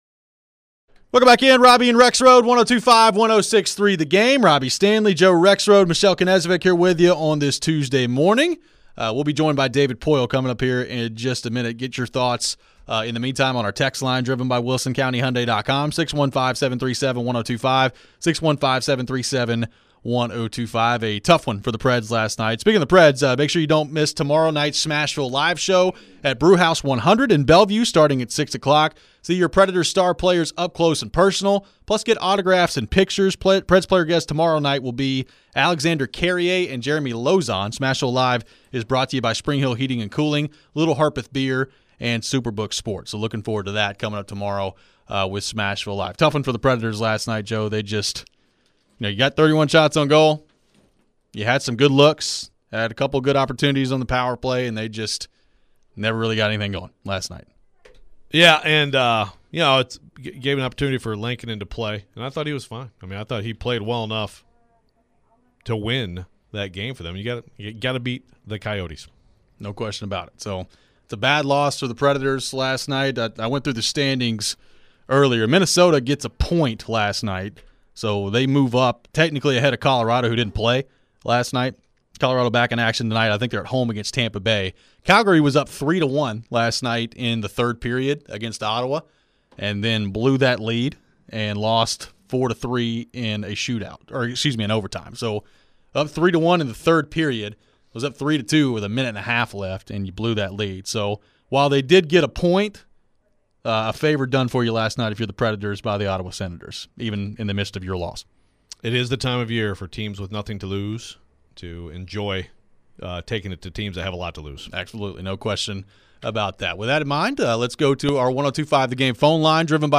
David Poile Interview (2-14-23)